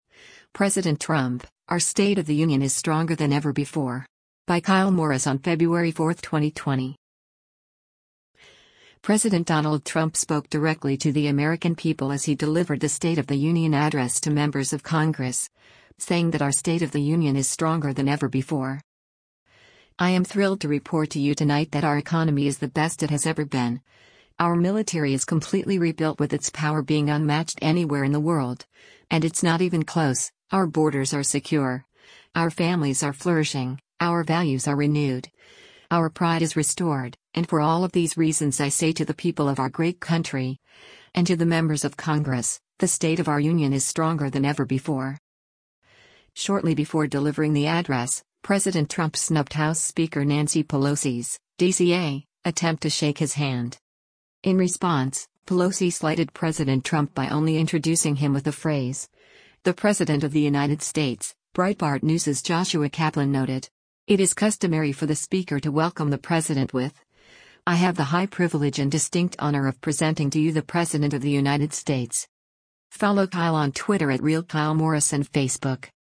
President Donald Trump spoke directly to the American people as he delivered the State of the Union address to members of Congress, saying that “our state of the union is stronger than ever before.”